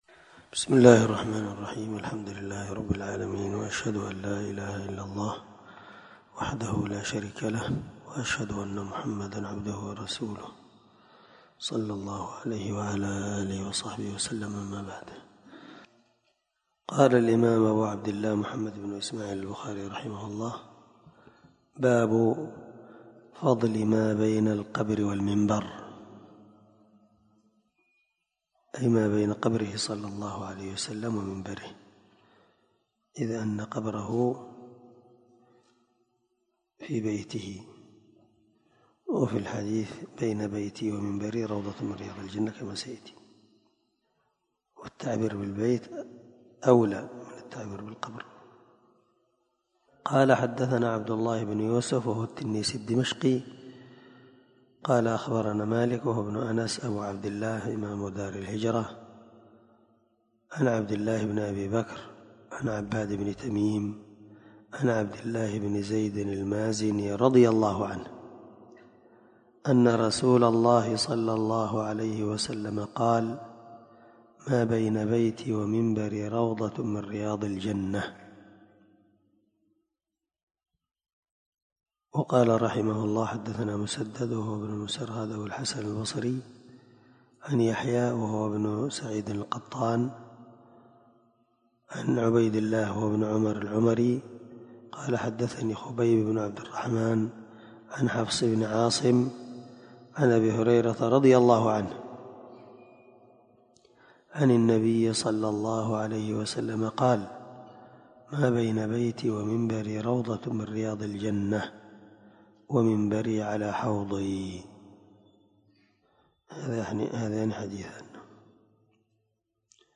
سلسلة_الدروس_العلمية
دار الحديث- المَحاوِل